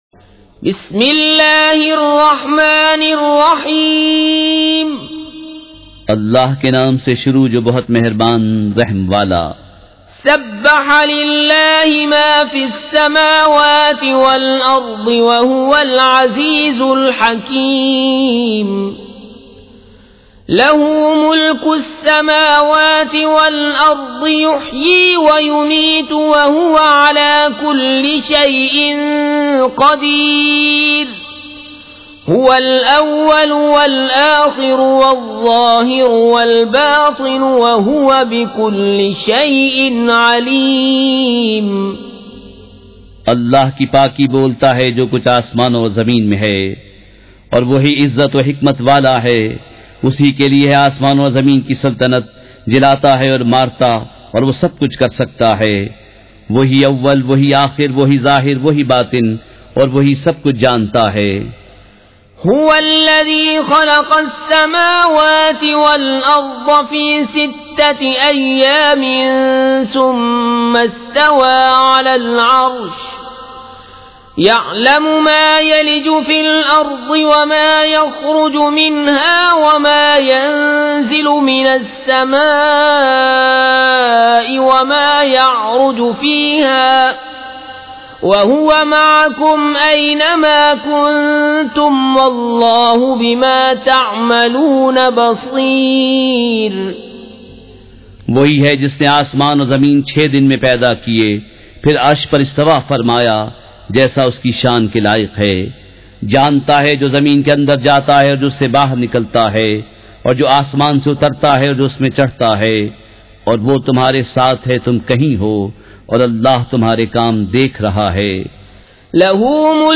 سورۃ الحدید مع ترجمہ کنزالایمان ZiaeTaiba Audio میڈیا کی معلومات نام سورۃ الحدید مع ترجمہ کنزالایمان موضوع تلاوت آواز دیگر زبان عربی کل نتائج 1887 قسم آڈیو ڈاؤن لوڈ MP 3 ڈاؤن لوڈ MP 4 متعلقہ تجویزوآراء